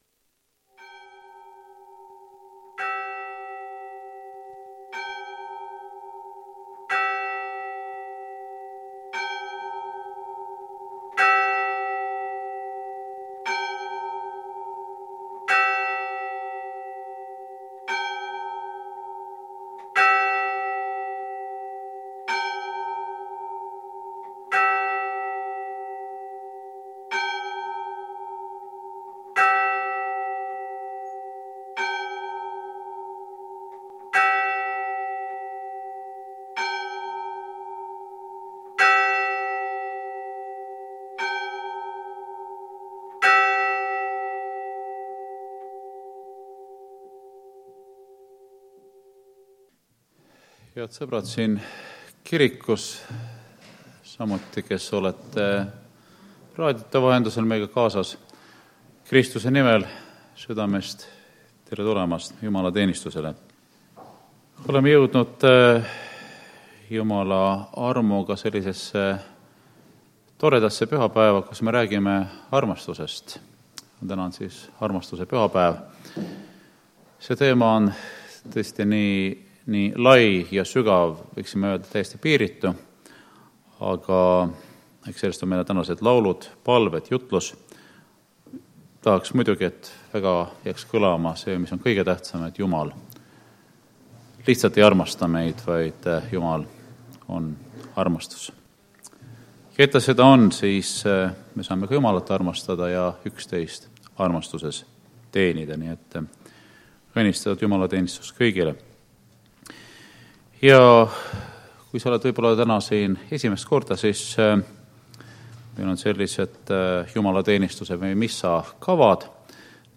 Jumalateenistus 3. oktoober 2021